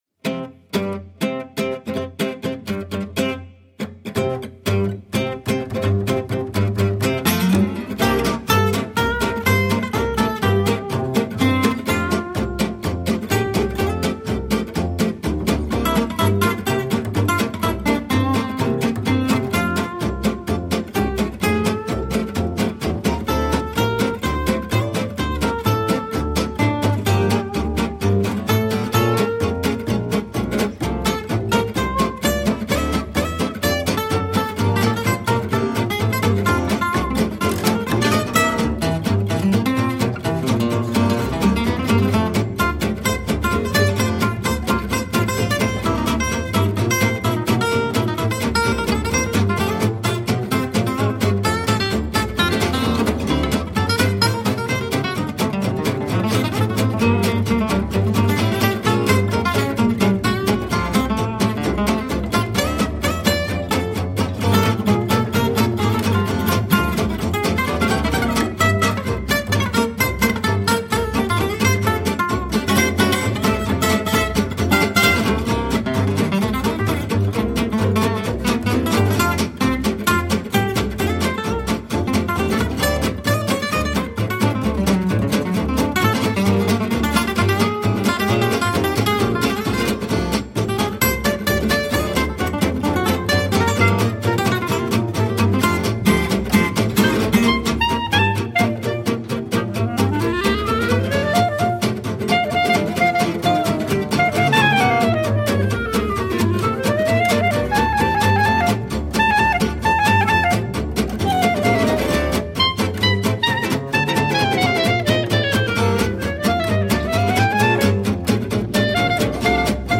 gypsy-jazz (o swing manouche)
chitarra, chitarra, contrabbasso